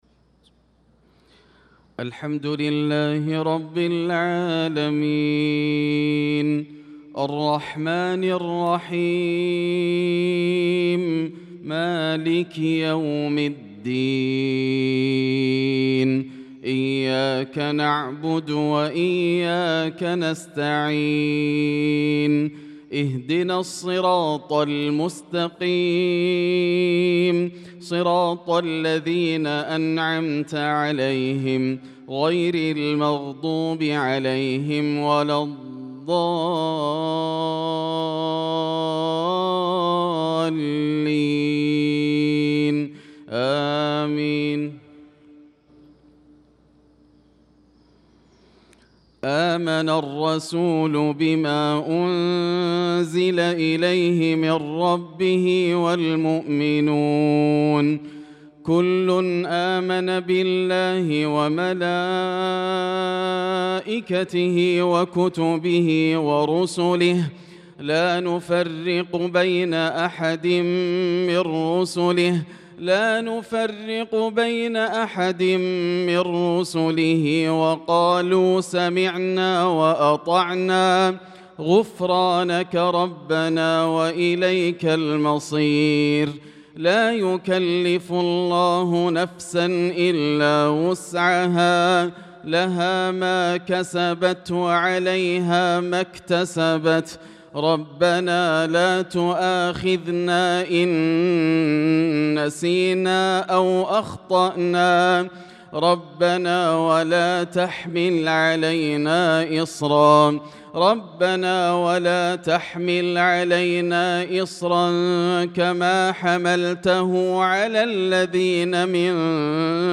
صلاة المغرب للقارئ ياسر الدوسري 10 شوال 1445 هـ
تِلَاوَات الْحَرَمَيْن .